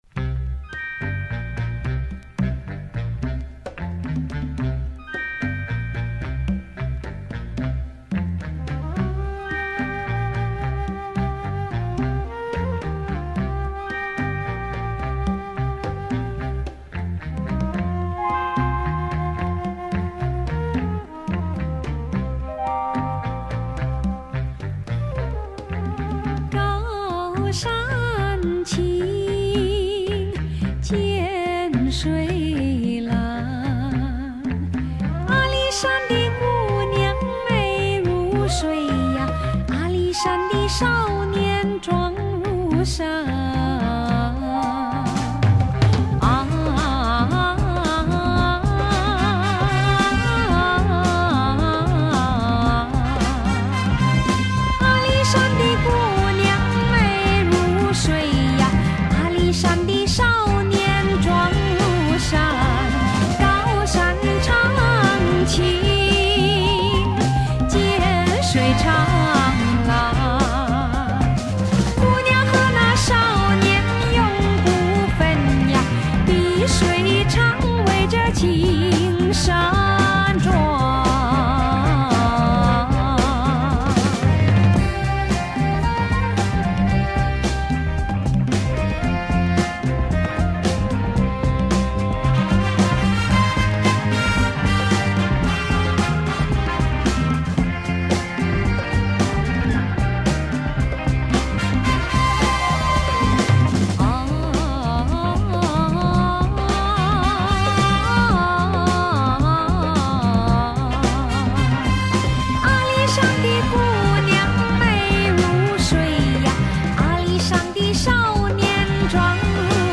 It's pop and jazz soul sung in different languages.
groovy pop soul with English words
melodic exotica jazz with oriental vocals.